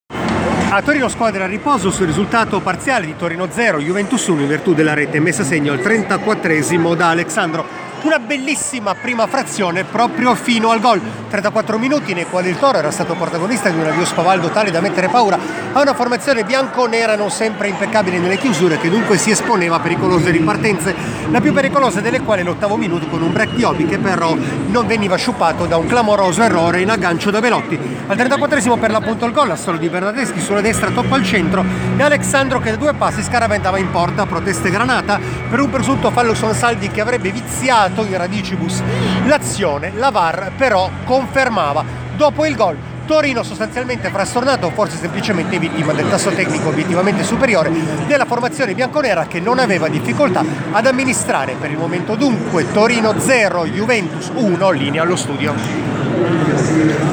IL COMMENTO